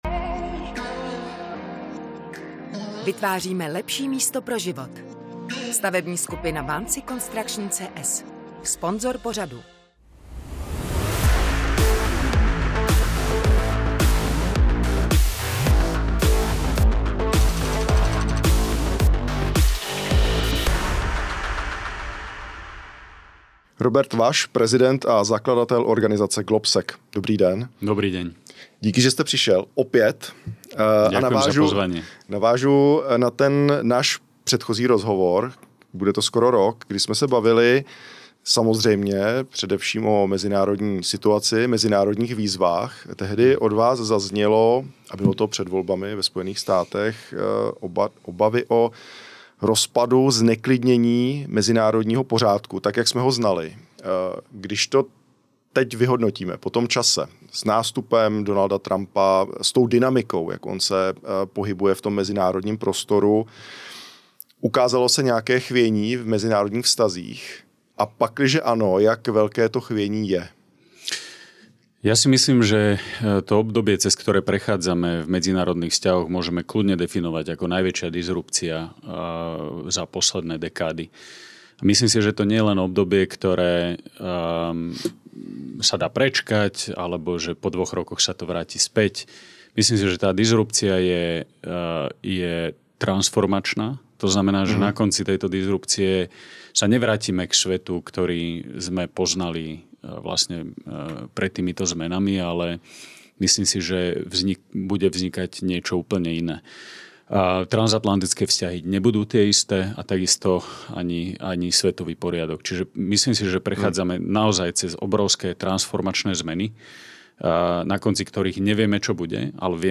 rozhovoru